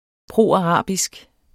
Udtale [ ˈpʁoɑˌʁɑˀbisg ]